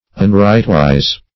Search Result for " unrightwise" : The Collaborative International Dictionary of English v.0.48: Unrightwise \Un*right"wise`\, a. Unrighteous.